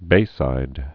(bāsīd)